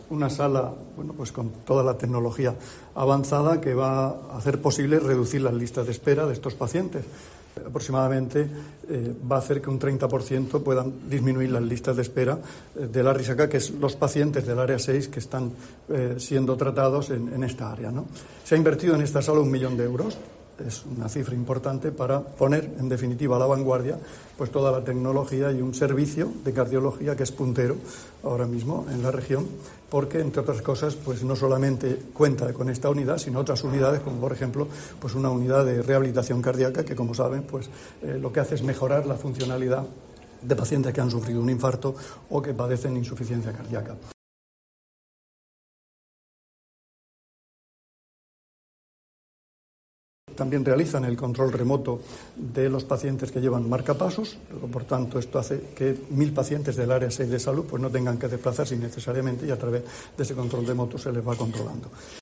Juan Antonio Pedreño, consejero de Salud